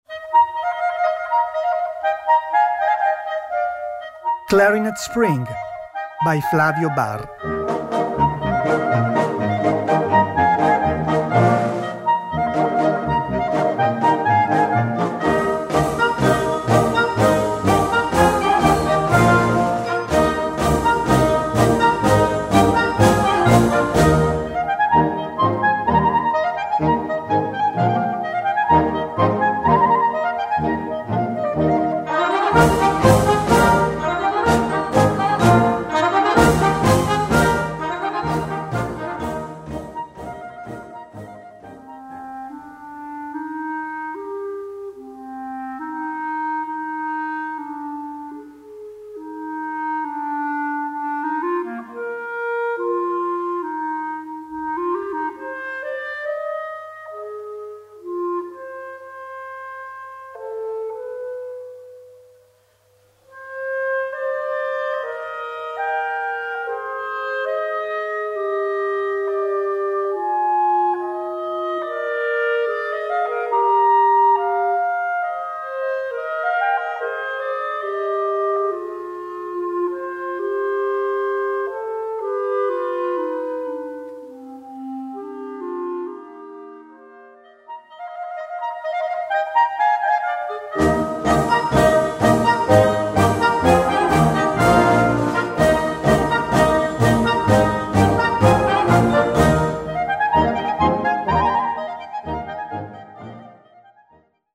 Gattung: für 2 Clarinets
Besetzung: Blasorchester